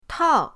tao4.mp3